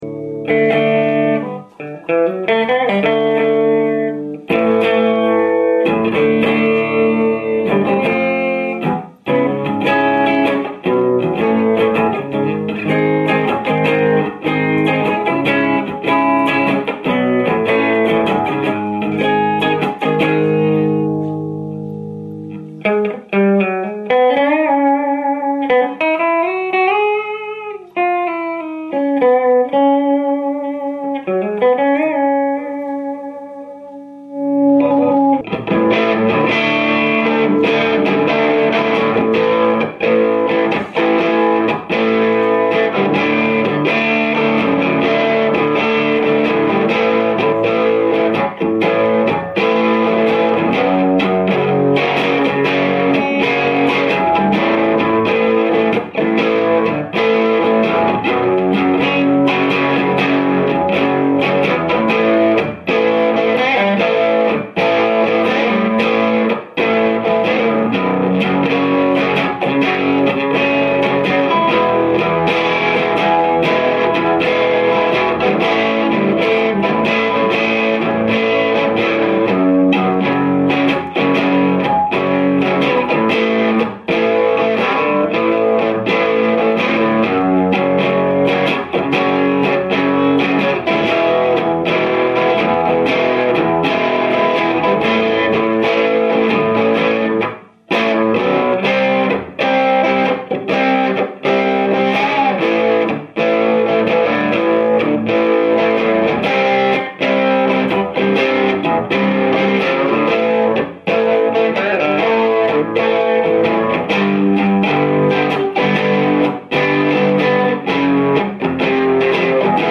Il chitarrista che avevo ordinato per i sample non è arrivato, quindi preparatevi a qualcosa di agghiacciante  :sick: :
Sample soma84 con cassa semiaperta 1x12
La registrazione è stata fatta a 2 metri di distanza con una videocamera. La chitarra (single coil) è attaccata diretta all'ampli.